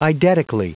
Prononciation du mot eidetically en anglais (fichier audio)
Prononciation du mot : eidetically